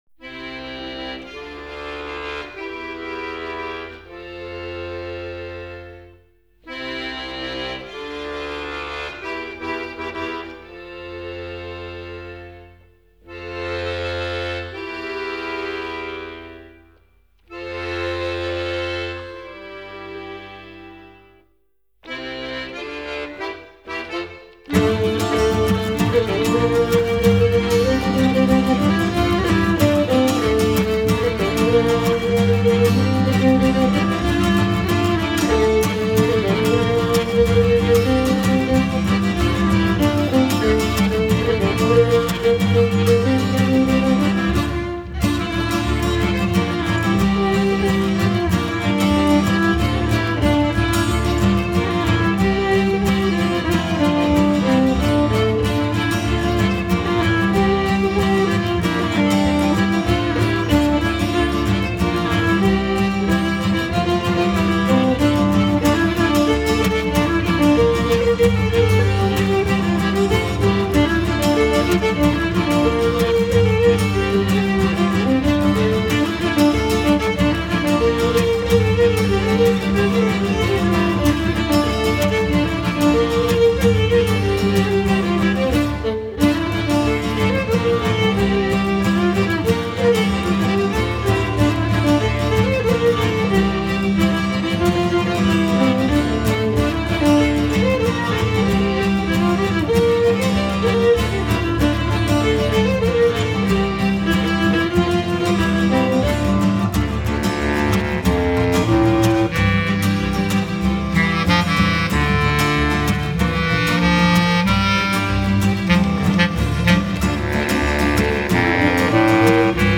Scottish                                     descrizione